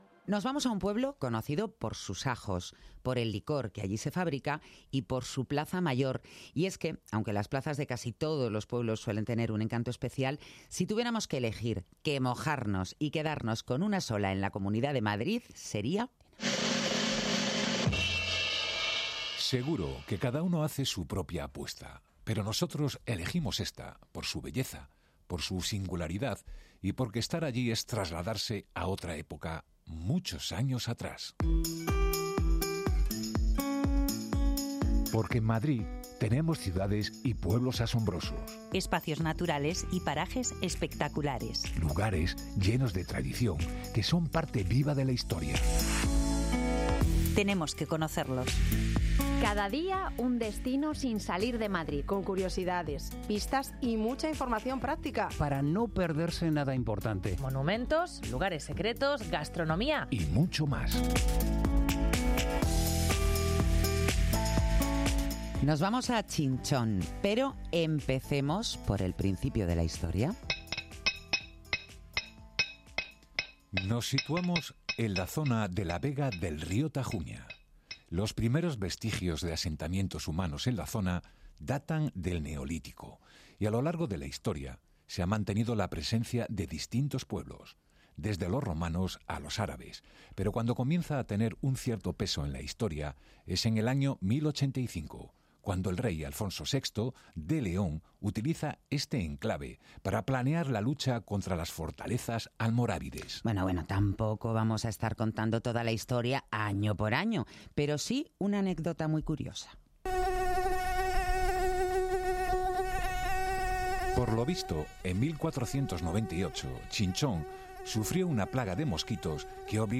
Dos horas de radio, desde la medianoche hasta las dos de la madrugada.
Dos hasta las dos es el magazine nocturno de Onda Madrid. Porque Madrid no duerme y hay muchas historias que contar de madrugada.